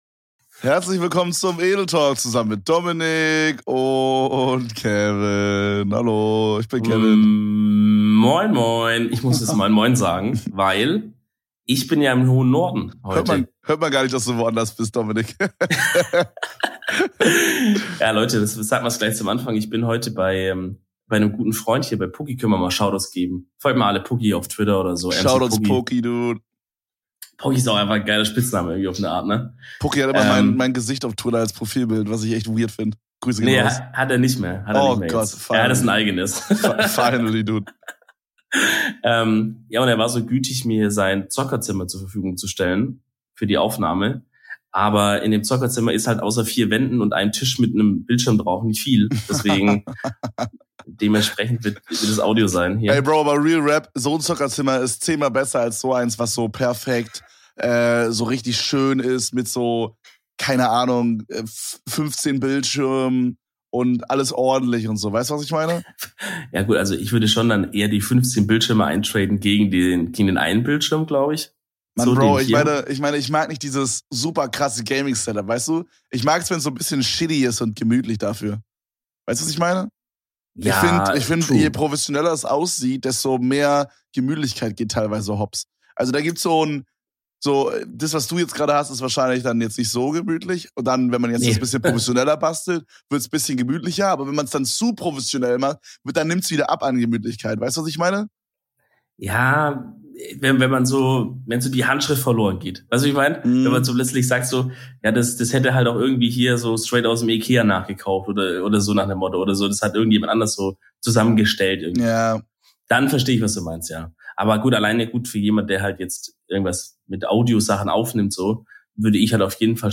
so ist es etwas halliger zugange.